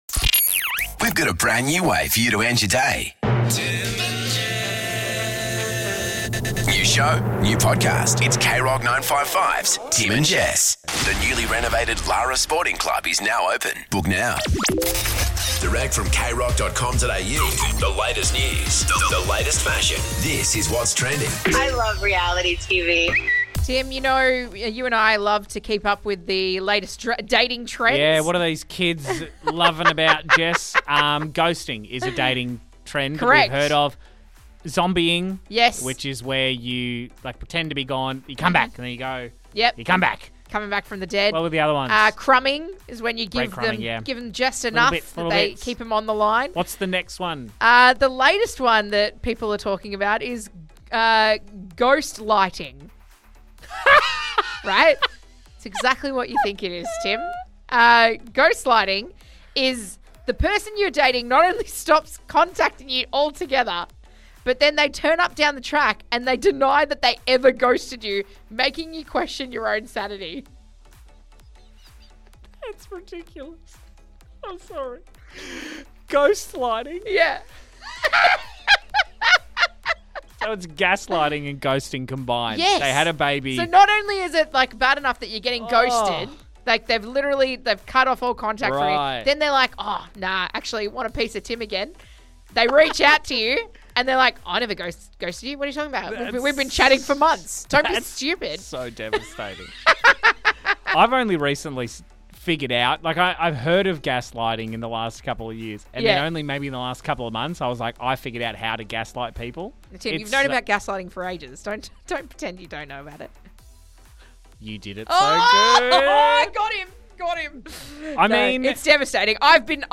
- There is a new dating trend - GUEST: Actor and Geelong local, Chloe Hayden